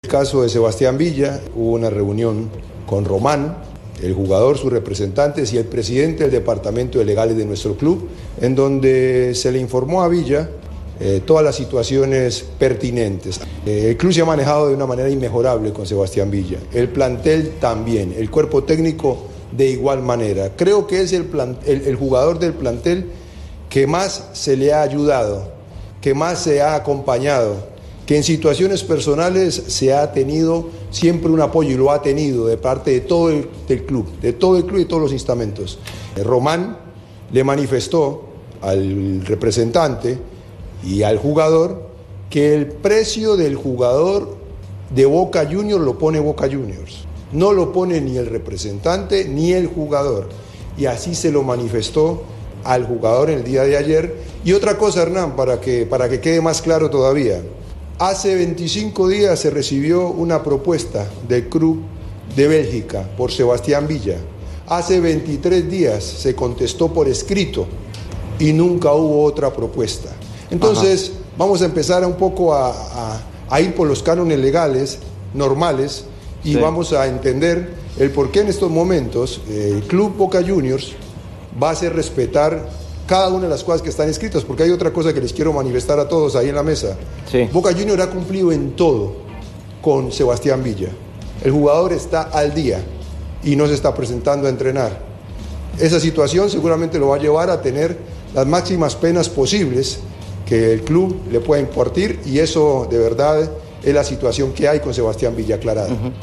Jorge Bermúdez, del Consejo de Fútbol de Boca Juniors, en entrevista con TNT Sports de Buenos Aires